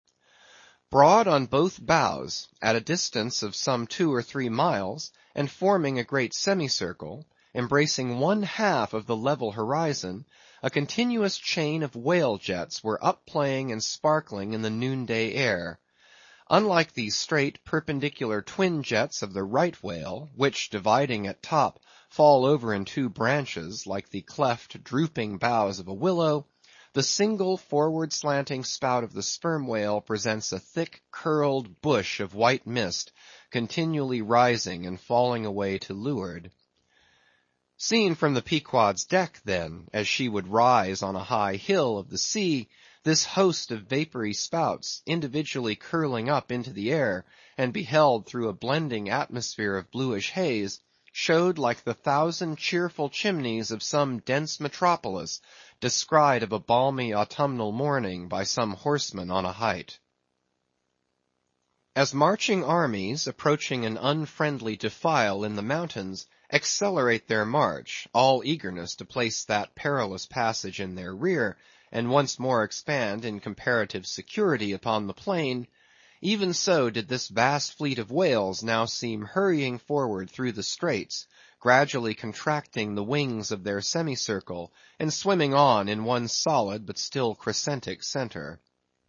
英语听书《白鲸记》第753期 听力文件下载—在线英语听力室